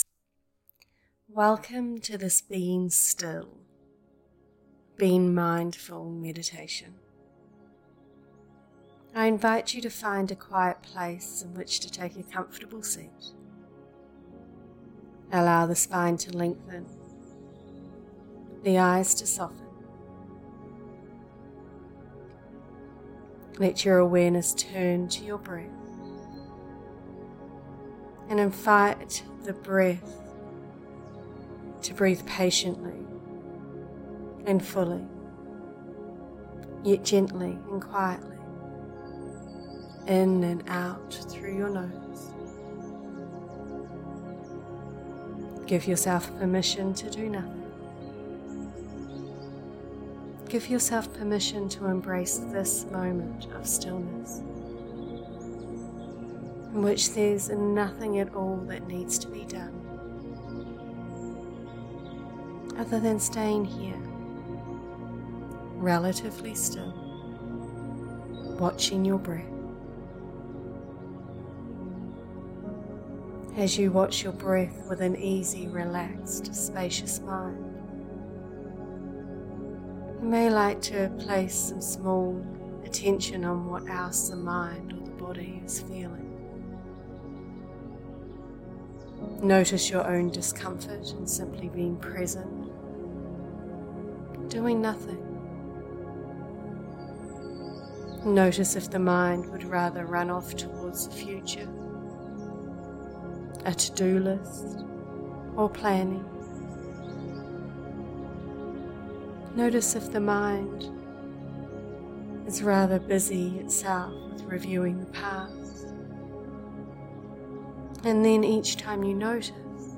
BEING STILL MEDITATION
beingstillmeditation.mp3